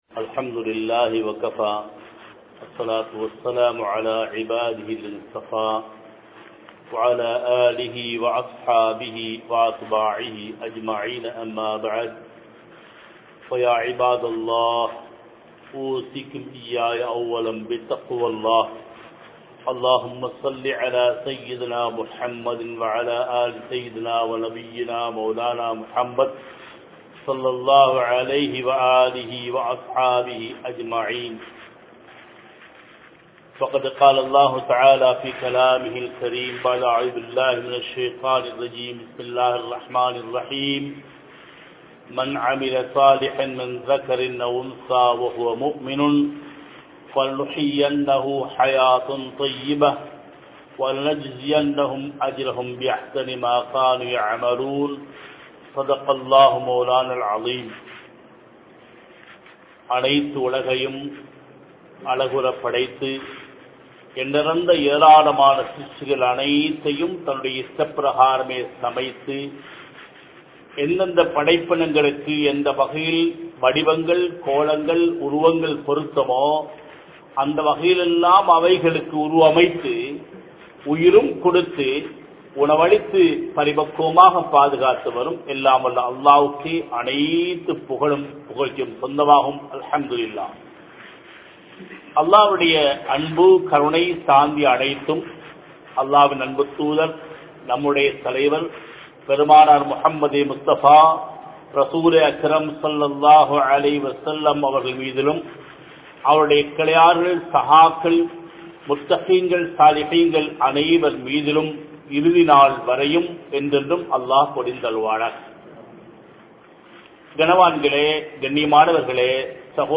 Manamaana Vaalkai Veanduma? (மனமான வாழ்க்கை வேண்டுமா?) | Audio Bayans | All Ceylon Muslim Youth Community | Addalaichenai